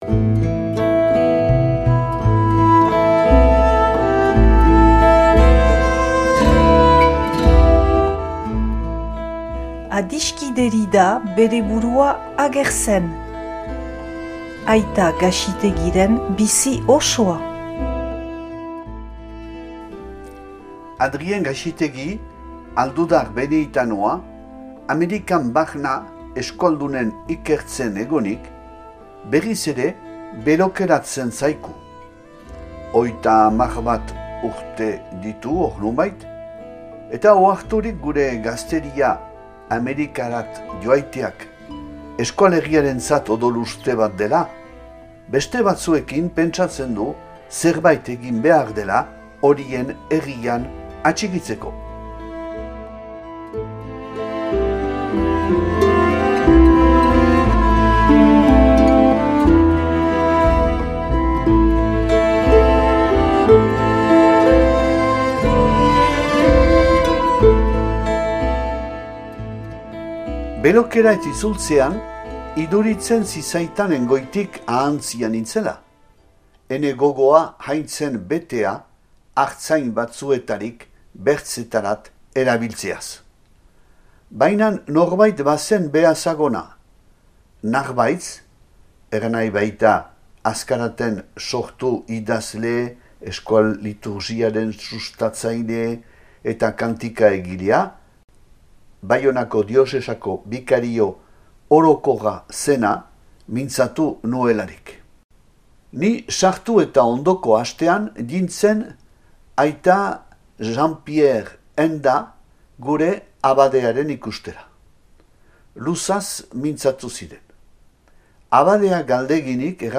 irakurketa bat dauzuegu eskaintzen